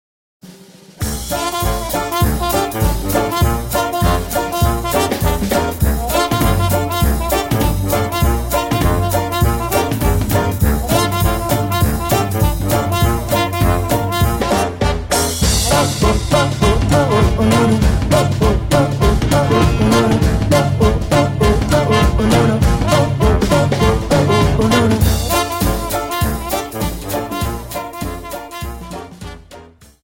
Dance: Quickstep Song
Quickstep 50